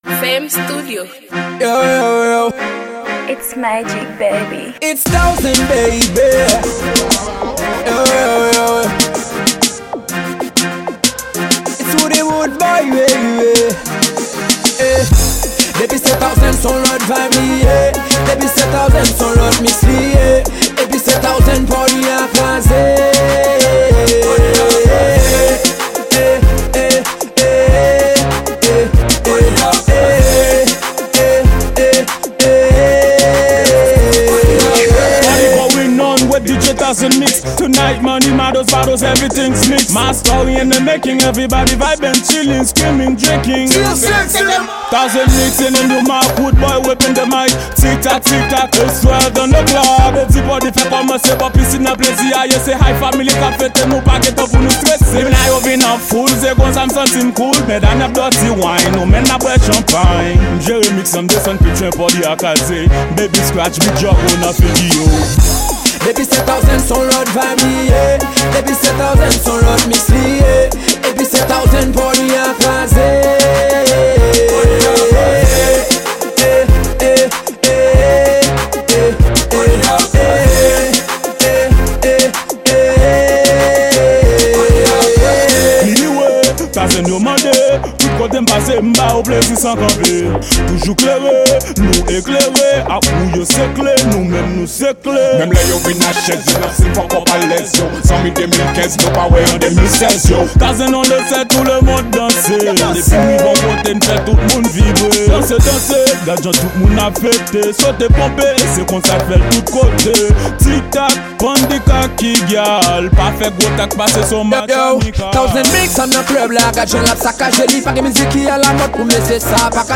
Genre: Dancehal.